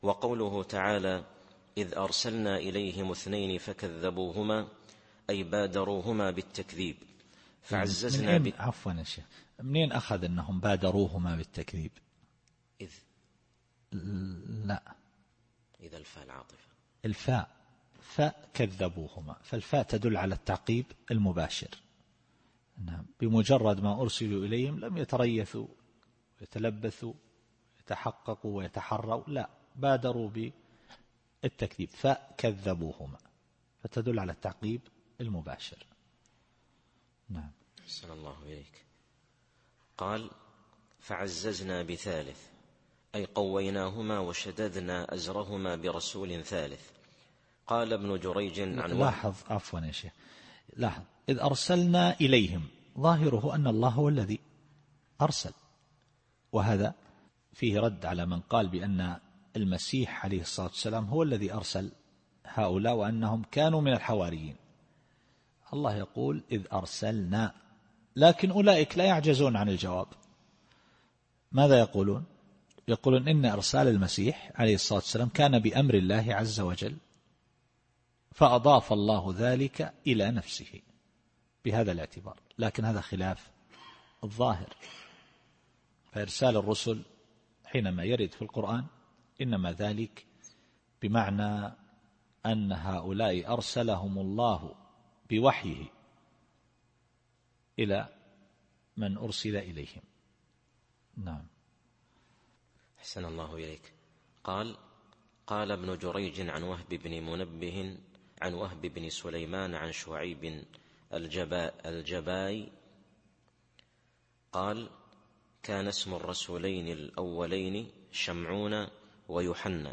التفسير الصوتي [يس / 14]